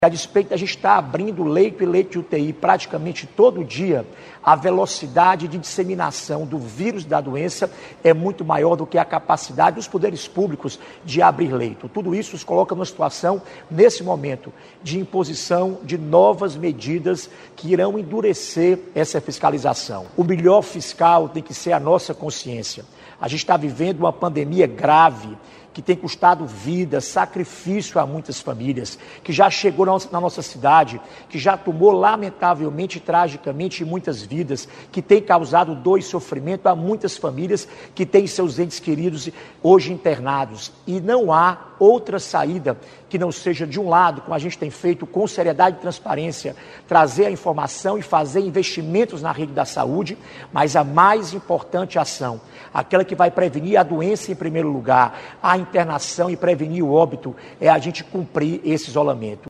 Ainda durante a transmissão, o governador Camilo Santana descartou o risco de desabastecimento de supermercados e farmácias.